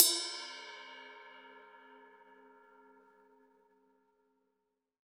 Index of /90_sSampleCDs/USB Soundscan vol.10 - Drums Acoustic [AKAI] 1CD/Partition C/02-GATEKIT 2